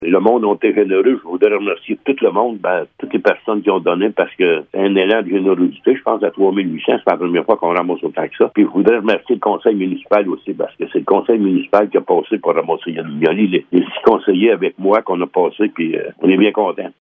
Malgré tout, la récolte de cette année a battu un record en atteignant la somme de 3 811 $. Le maire de Blue Sea, Laurent Fortin, se réjouit de cette nouvelle :